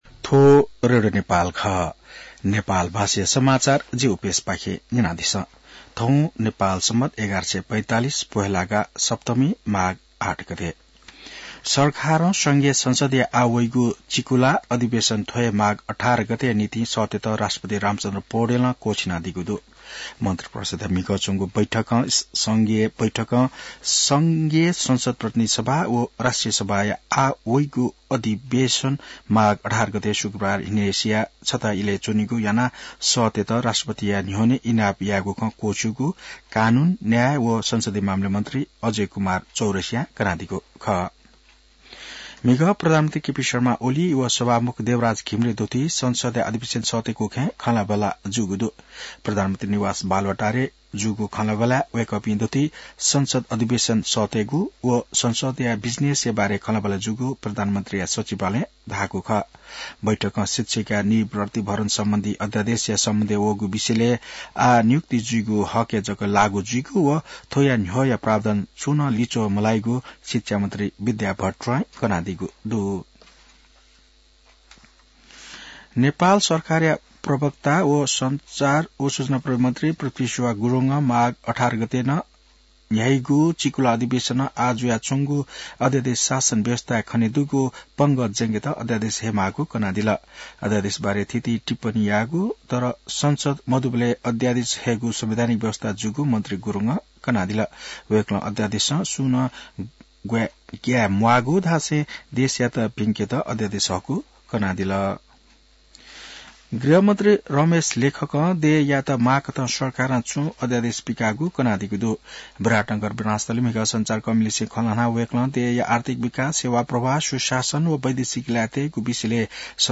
नेपाल भाषामा समाचार : ९ माघ , २०८१